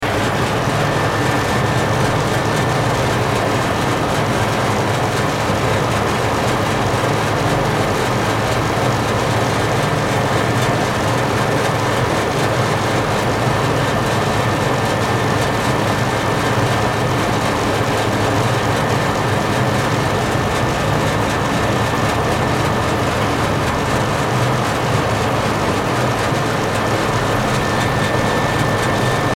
Large Industrial Fan Motor Running Sound Effect
Description: Large industrial fan motor running sound effect. Realistic loopable large fan motor sound effect.
Large-Industrial-fan-motor-running-sound-effect.mp3